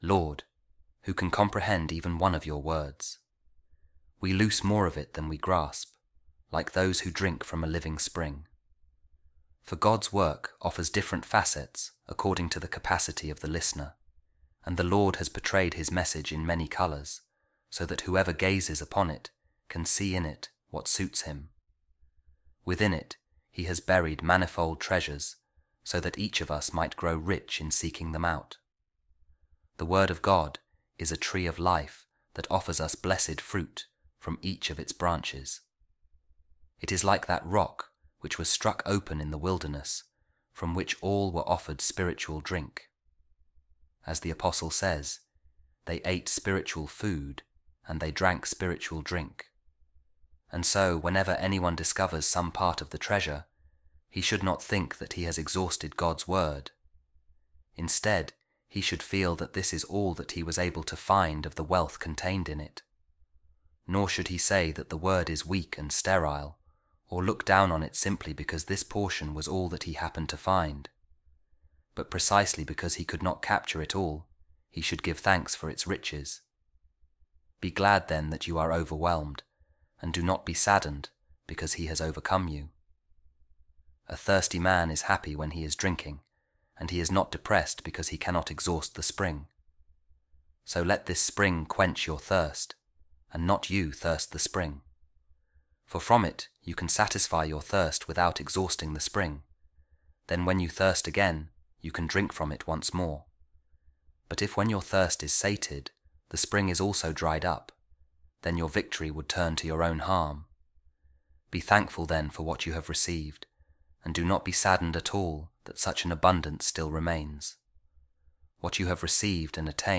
A Reading From The Commentary Of Saint Ephraem On The Diatesseron | The Word Of God Is An Inexhaustible Fountain